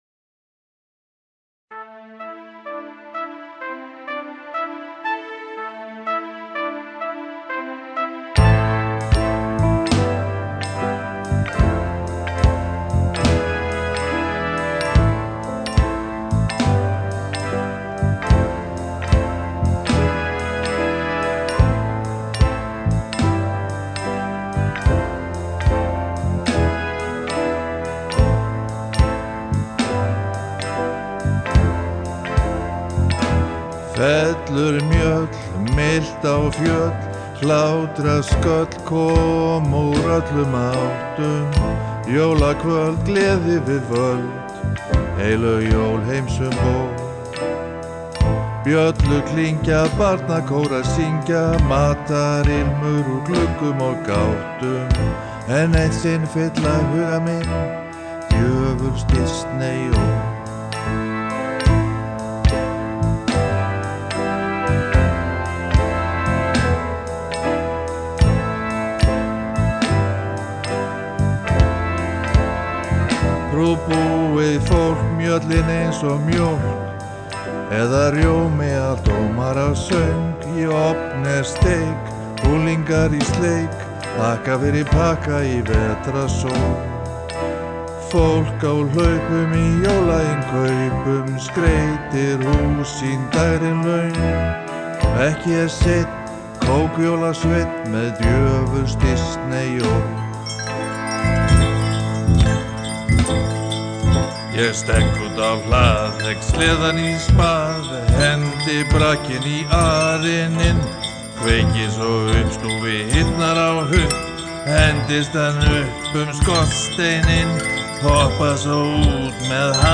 Söngur og hljóðfæraleikur